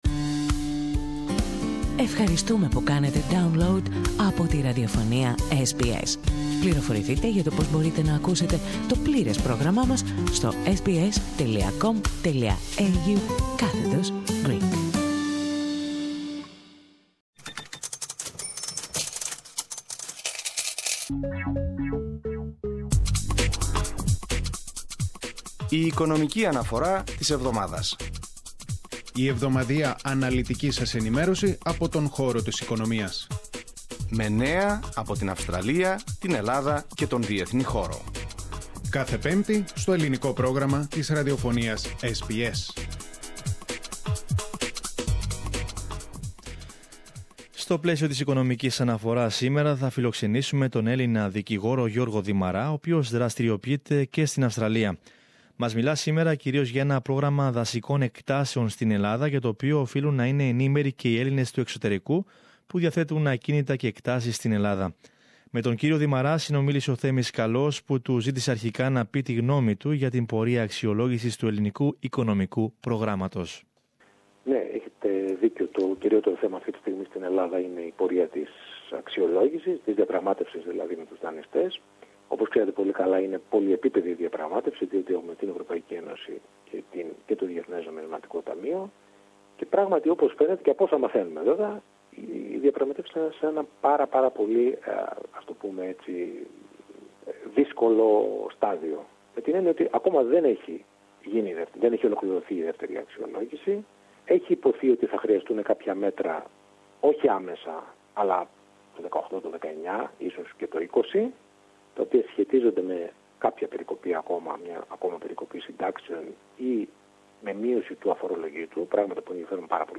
As part of our weekly Finance Report, we talk today to Greek lawyer